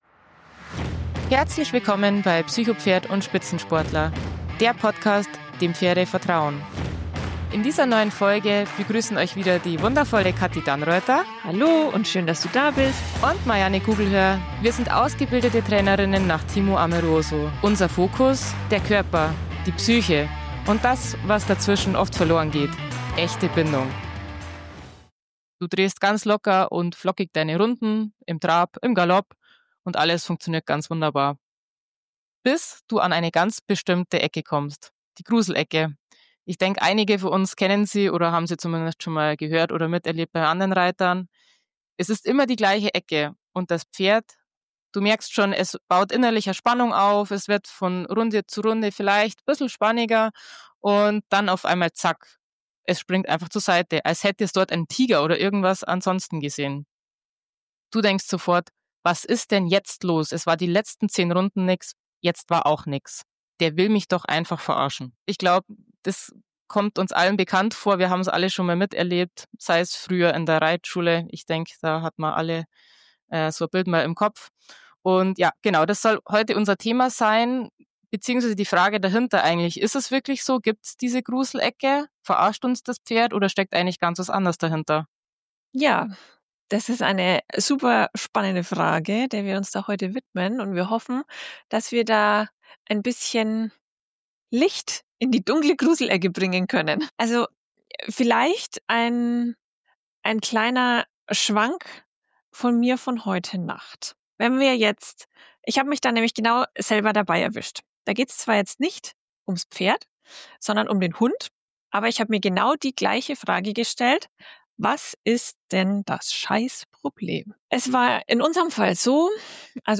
Wir beleuchten Gemeinsamkeiten, Unterschiede und was wir daraus für den Umgang miteinander – und mit uns selbst – lernen können. Freut euch auf ein ehrliches, reflektiertes und inspirierendes Gespräch, das neue Perspektiven aufzeigt und Impulse für ein tieferes Verständnis zwischen Mensch und Pferd gibt.